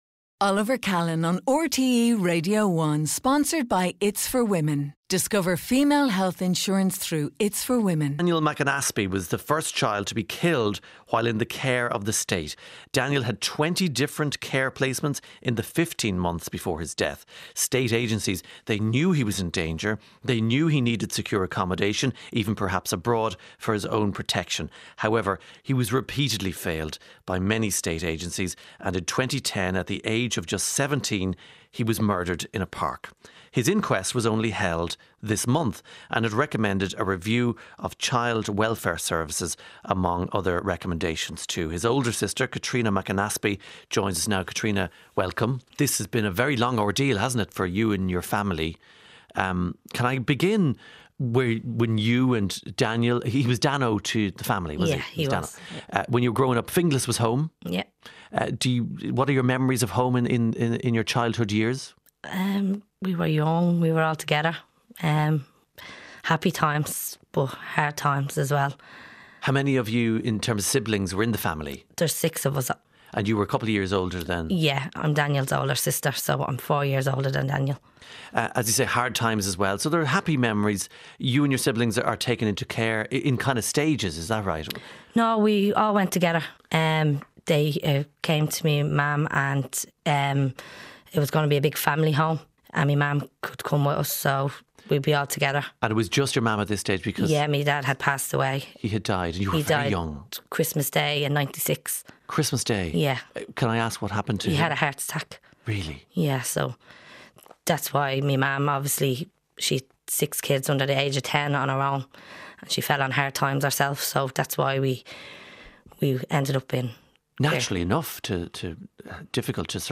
Please note, this conversation contains distressing details